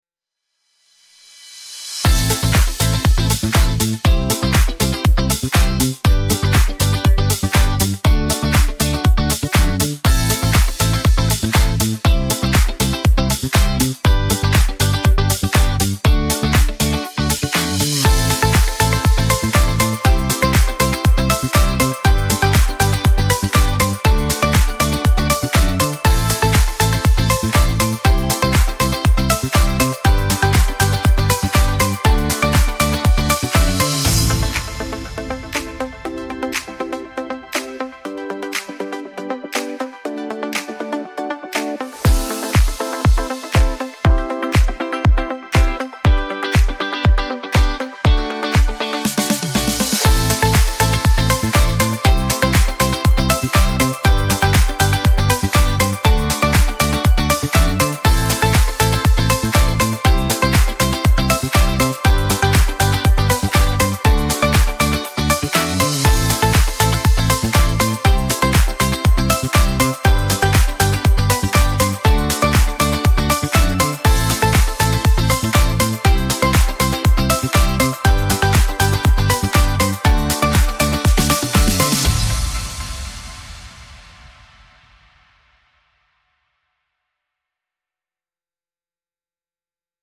playful party music with bouncy rhythms and joyful energy